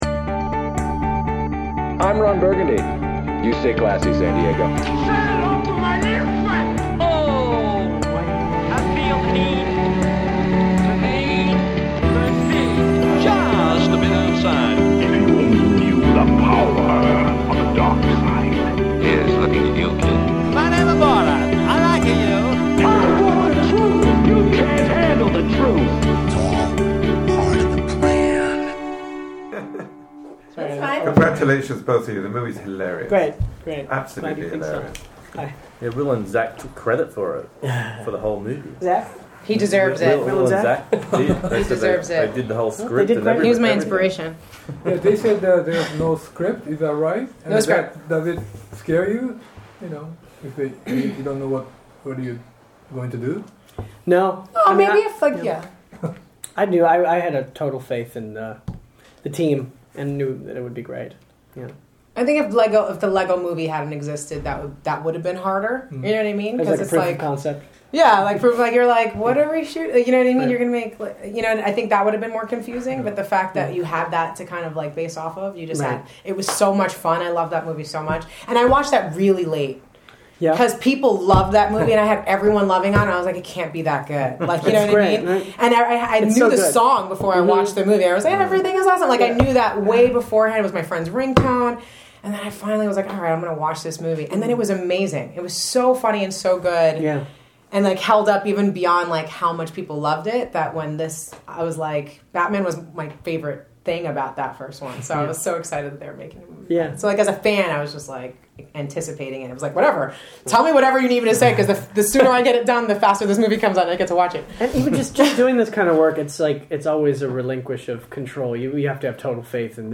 Entrevista: Rosario Dawson y Michael Cera – The LEGO Batman Movie
Entrevista a Rosario Dawson y Michael Cera de The LEGO Batman Movie.